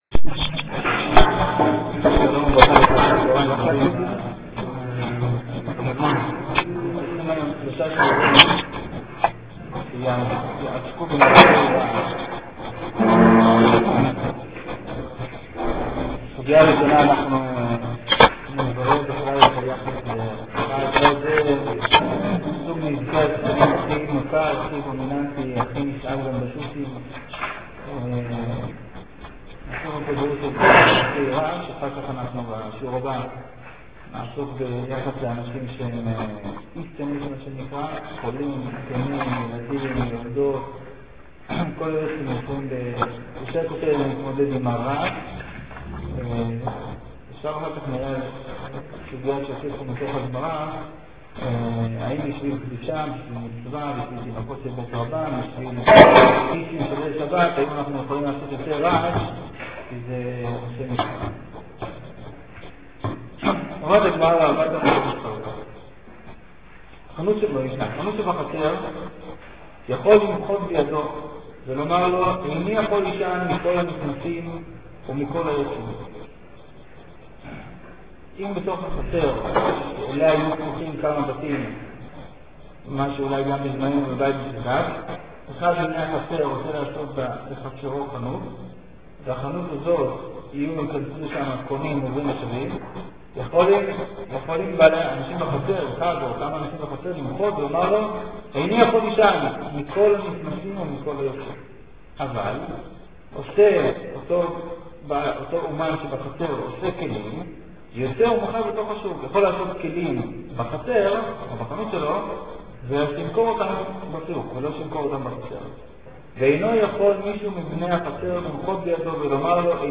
הלכות שכנים- שיעור מס.4 הורד קובץ (במידה ולא יורד הקובץ לחץ לחצן ימני של העכבר + Save target as...)